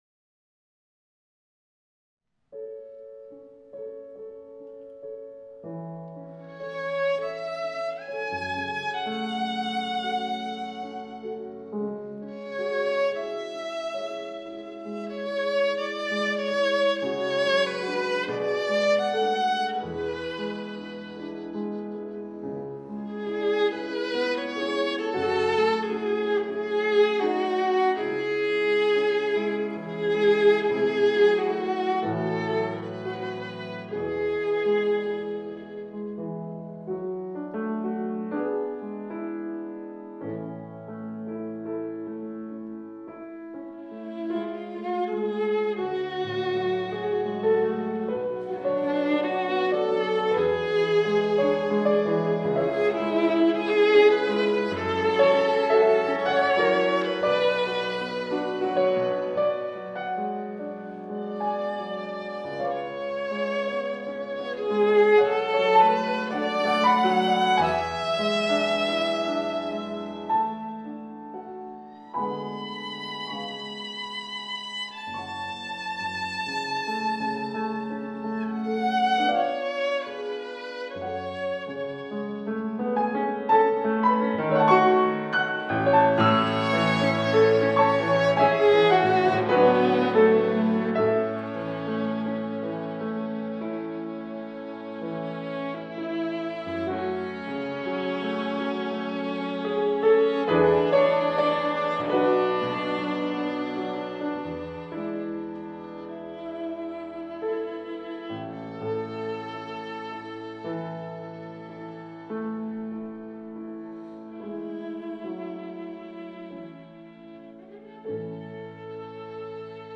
PIANISTKA Młodego pokolenia PRZESŁUCHAJ UTWORY